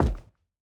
added stepping sounds
Plastic_001.wav